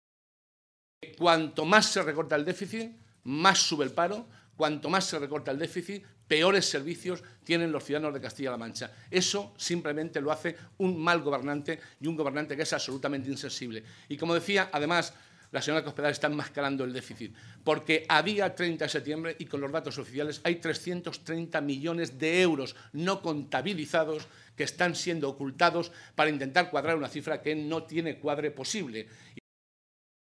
José Molina, coordinador de Economía del Grupo Parlamentario Socialista
Cortes de audio de la rueda de prensa